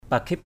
/pa-kʱɪp/ (đg.) bít làm cho kín, bịt, mím= boucher, fermer hemétiquement. pakhip bambeng F%A{P bO$ đóng kín cánh cửa. pakhip cambuai F%A{P c=O& mím môi lại; câm miệng.